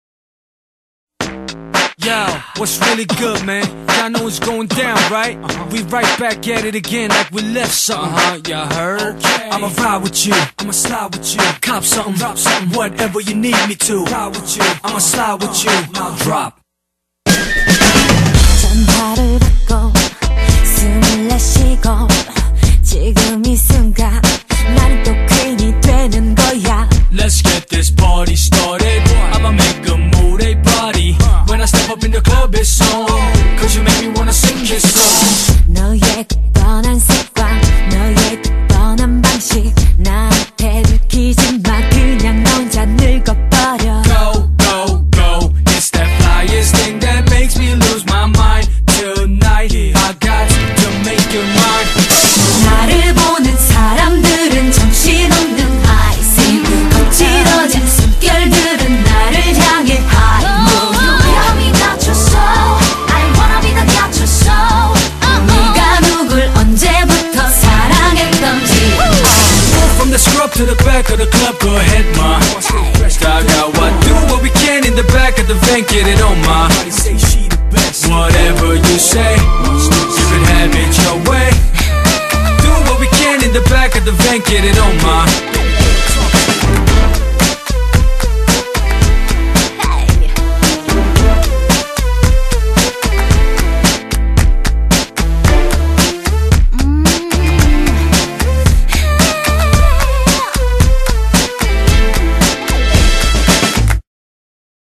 BPM112--1
Audio QualityPerfect (High Quality)